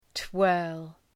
Προφορά
{twɜ:rl}